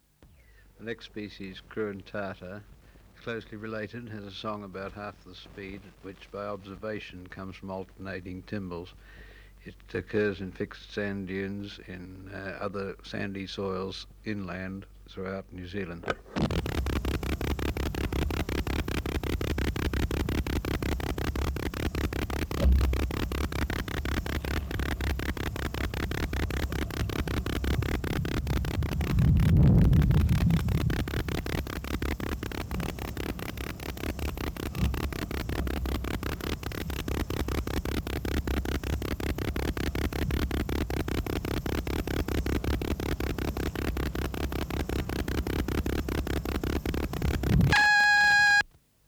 Natural History Museum Sound Archive Species: Rhodopsalta cruentata
Recorder: Uher portable